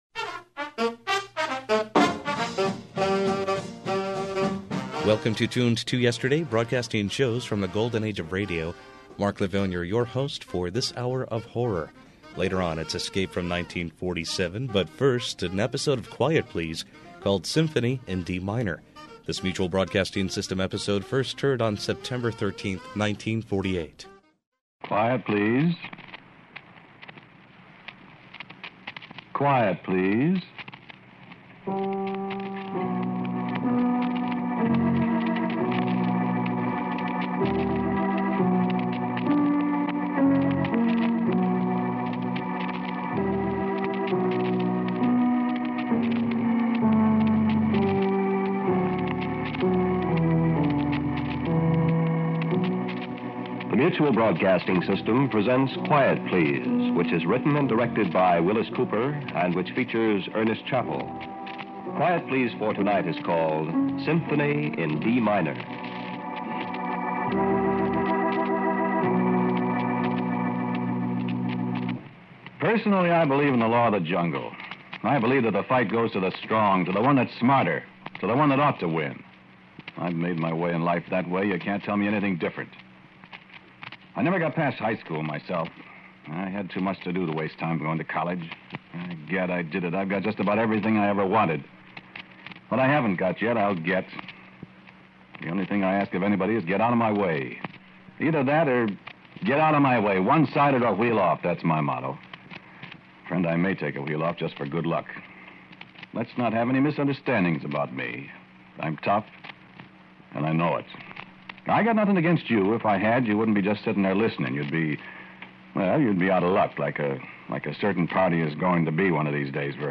The highest quality broadcasts are restored and played as they were heard years and years ago.
# Audio Drama